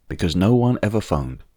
Hi, here are some samples of the final ‘d’, ‘t’ etc. sounds disappearing or becoming very faint after using de-clicker.